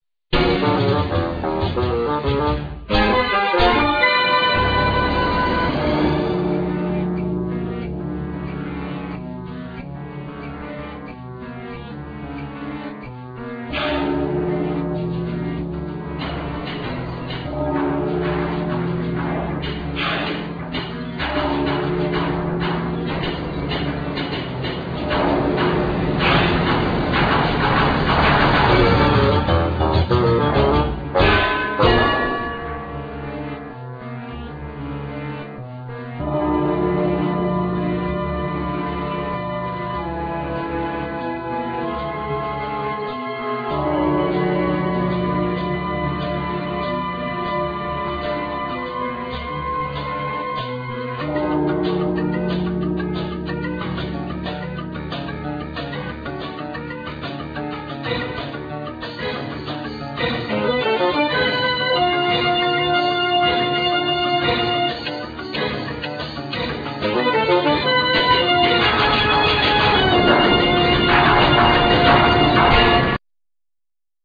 Violin
Trumpet